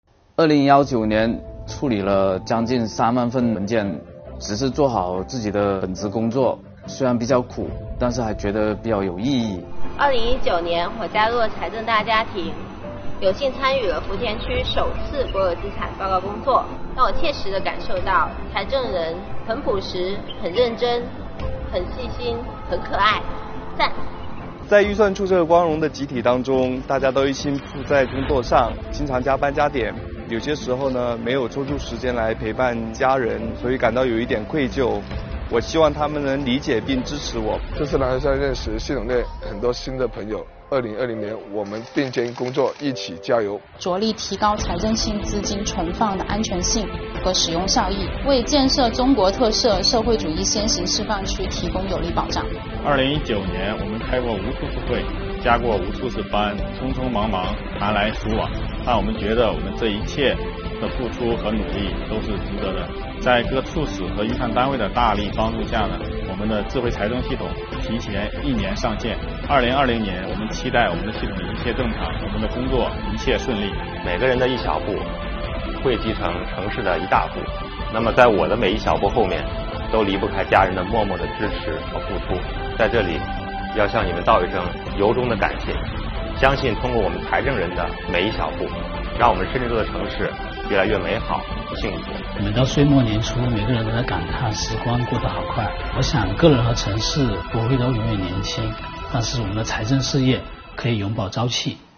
干部职工话新年！